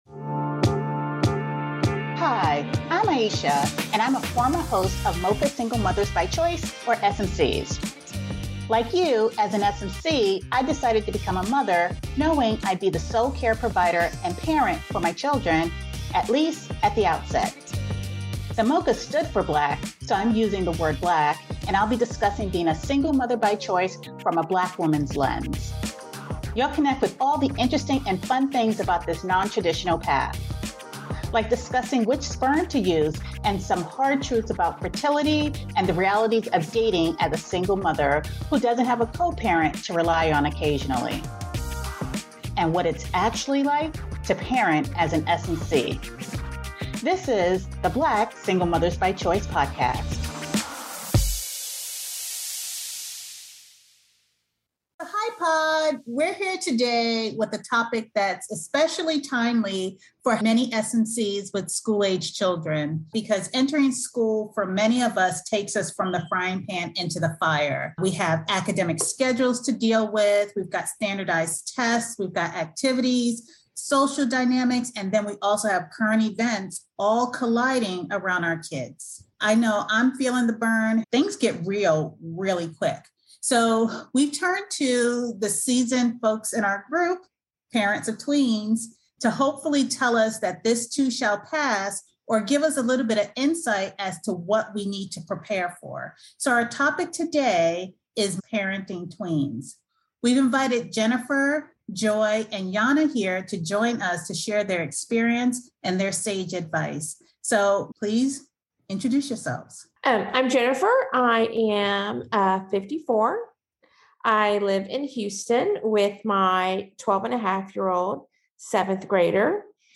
Join us for candid conversations, sage advice, and heartfelt stories.